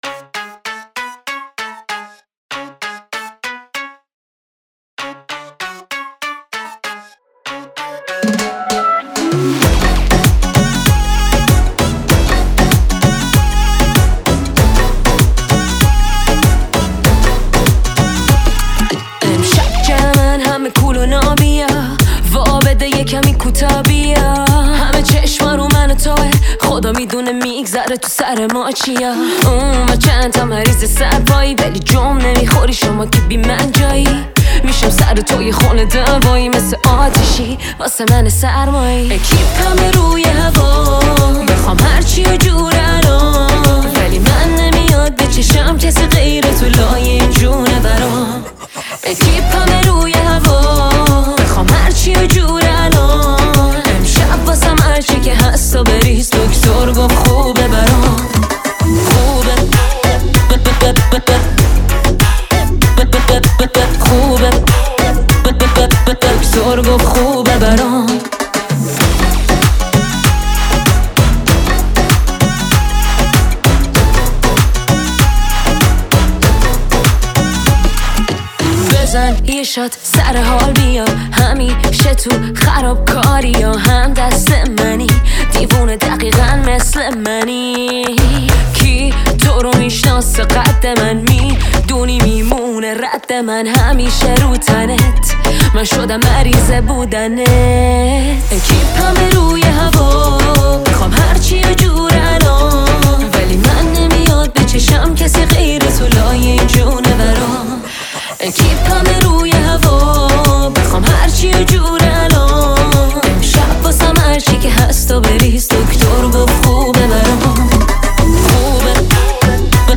موزیک جدید و شاد